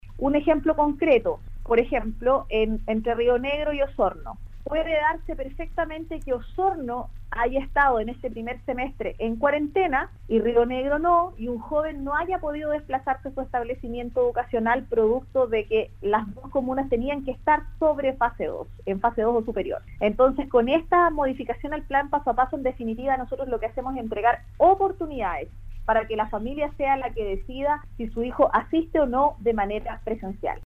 En conversación con Radio Sago la Seremi de Educación Paulina Lobos se refirió a las medidas del nuevo Plan Paso a Paso y el retorno presencial a clases.